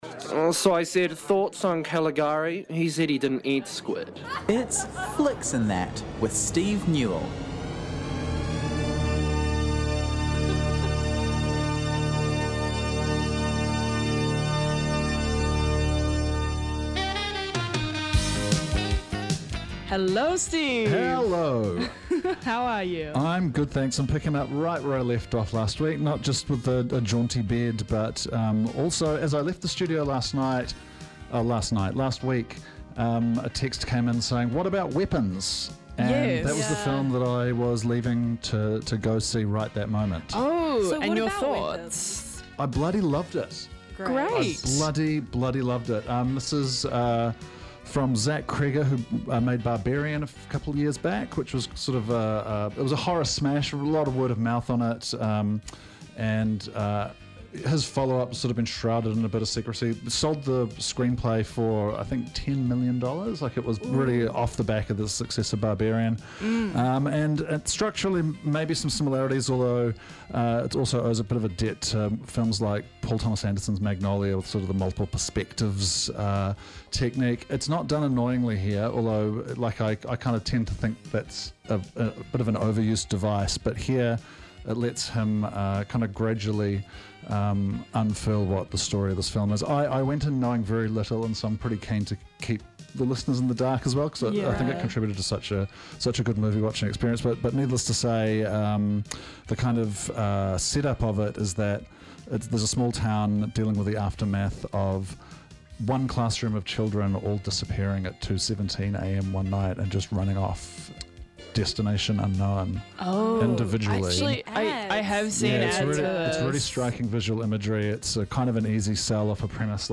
From Son, Rhumba, and Cuban music to Salsa, Puerto Rican and beyond.